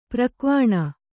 ♪ prakvāṇa